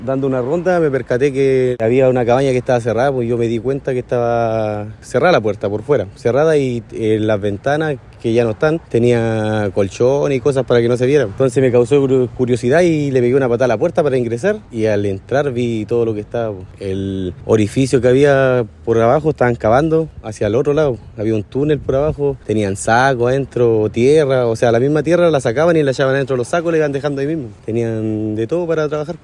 cuna-testigo-tunel-1.mp3